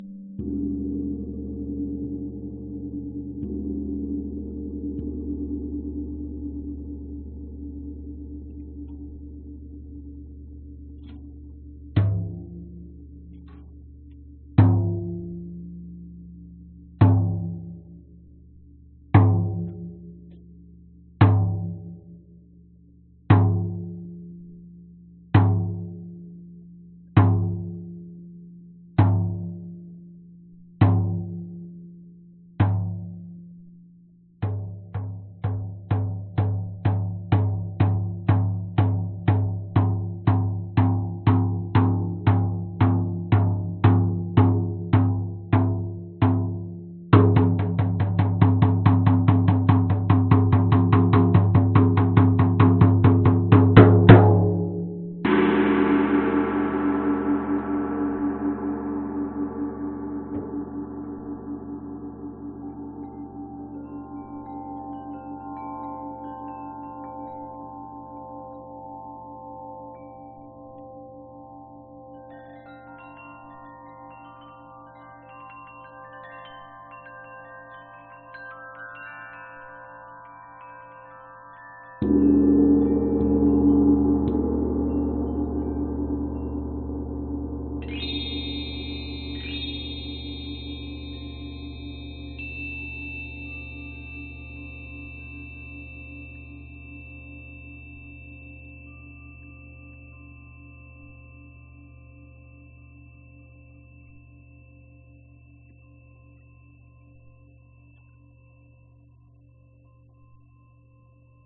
描述：这首现场曲目样本以水晶碗、锣鼓和人声调子为特色，伴随着水晶碗的声音。
标签： 水晶碗 声音治疗 治疗音乐 振动 风铃 器乐
声道立体声